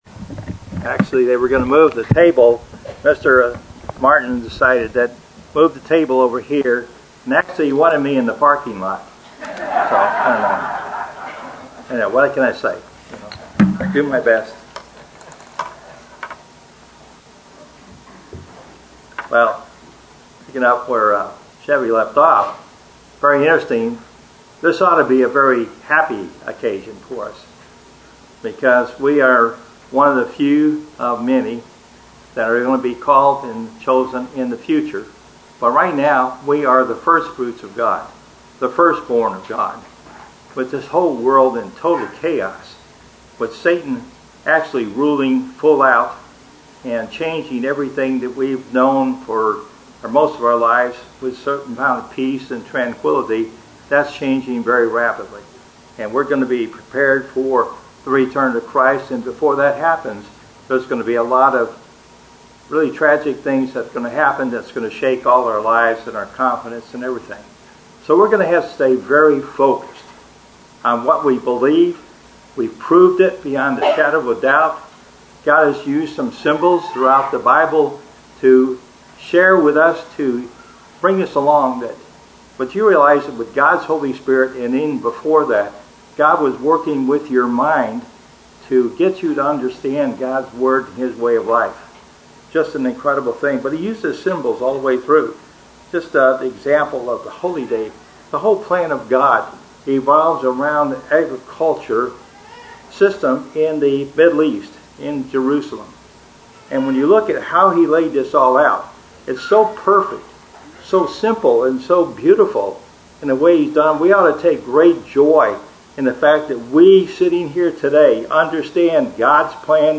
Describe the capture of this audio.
Given in Fort Worth, TX